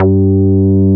P MOOG G3MP.wav